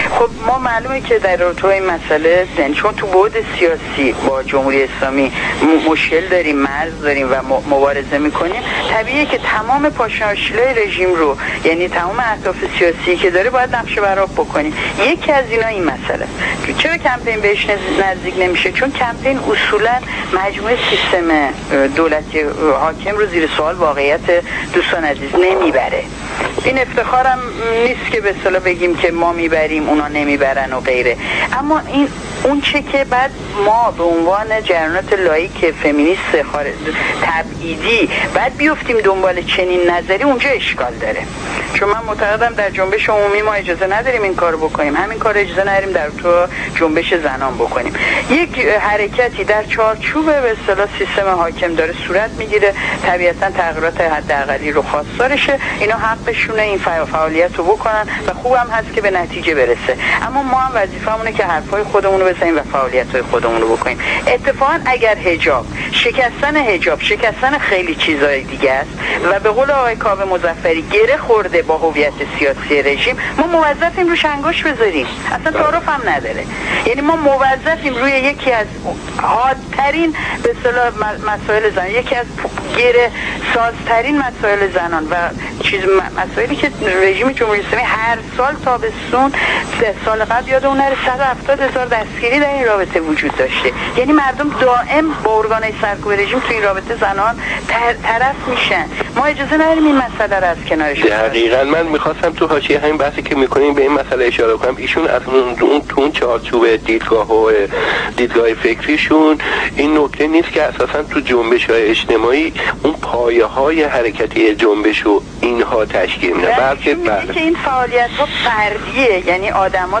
مصاحبه رادیو